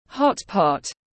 Lẩu tiếng anh gọi là hot pot, phiên âm tiếng anh đọc là /ˈhɒt.pɒt/
Hot pot /ˈhɒt.pɒt/